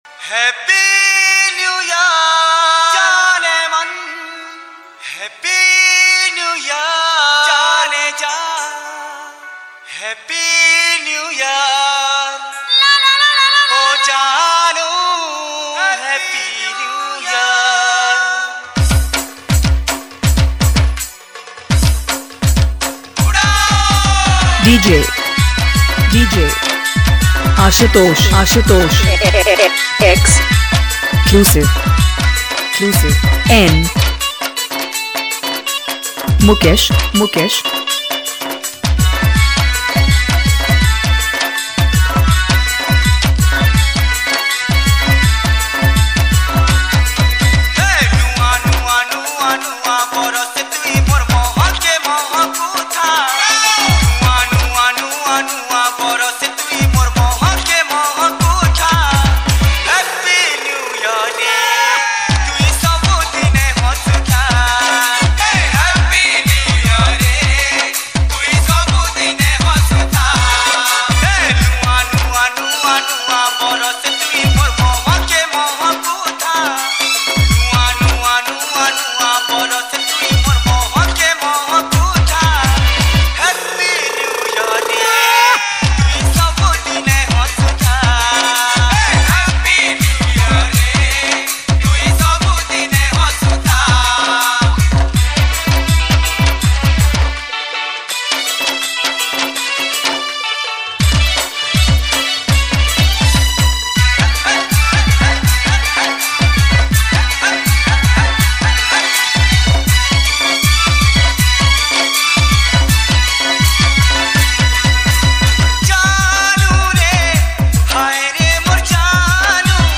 New Year Special Dj Remix Songs Download